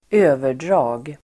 Uttal: [²'ö:ver_dra:g]